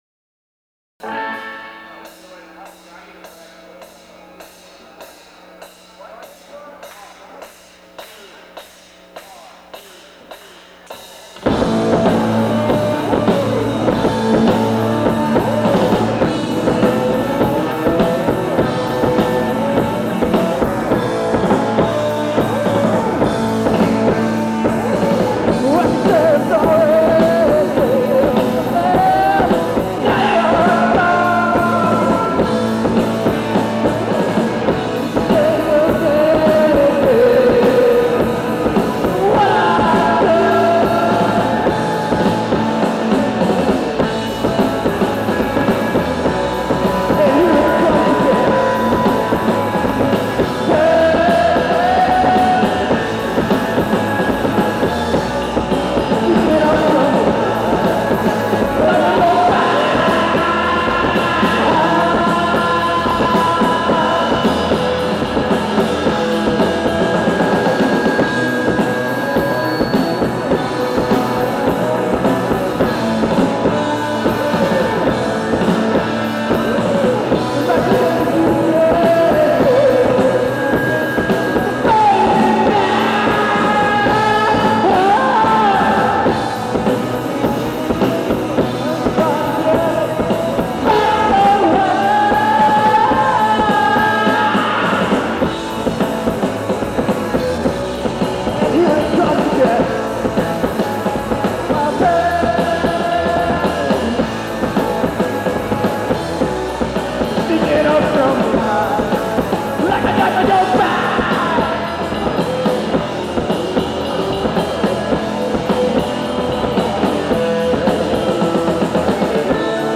Live Tapes
Houston Hall Auditorium – Penn 09/16/1989